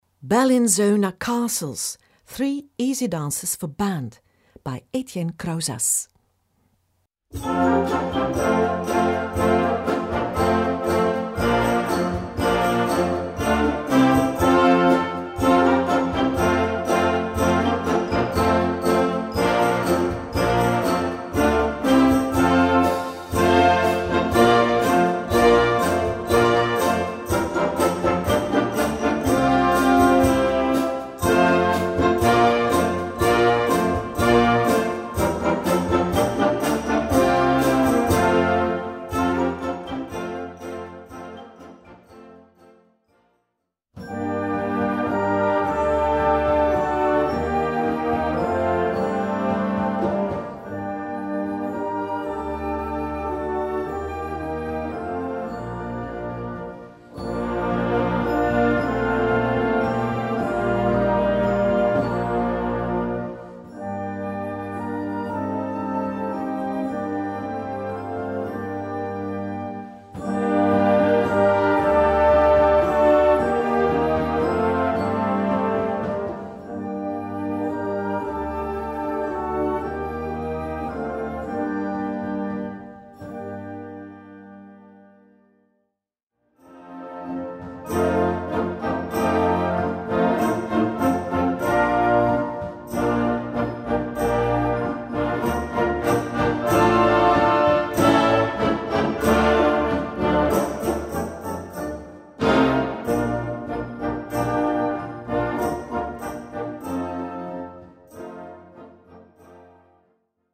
Besetzung: Ensemble gemischt